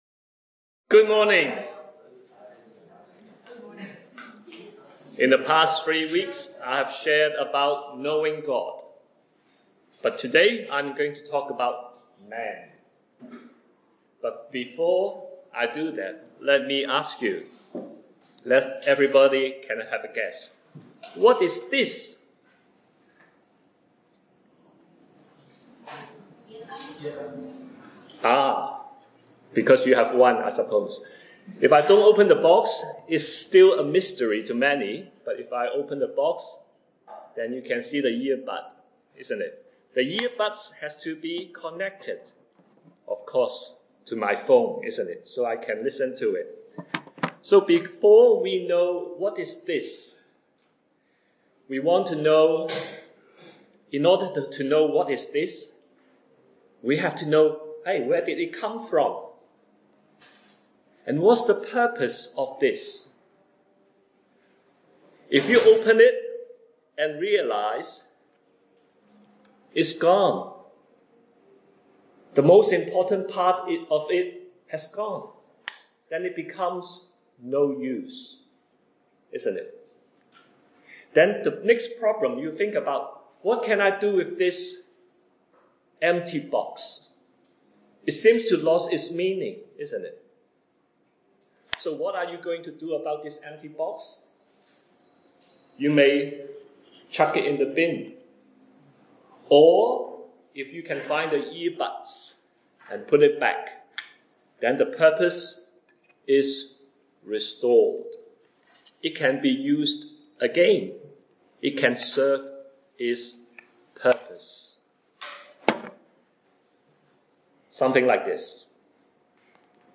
Scripture reference: Genesis 1:26-28 A short talk on the purpose of life for man and how God restores sinners through the redemption that has come through our Lord Jesus.